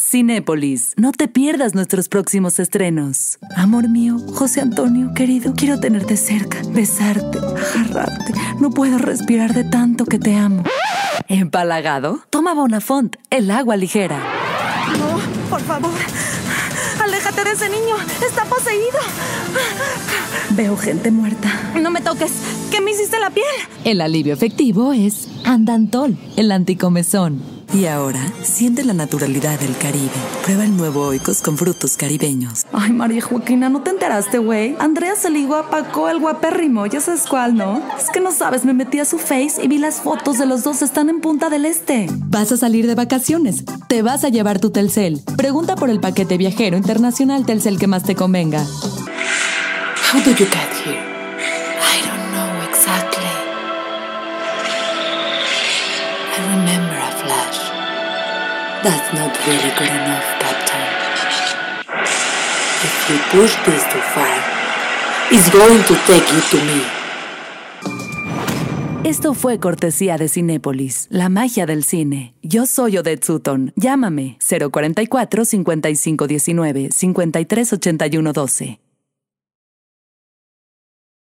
时尚性感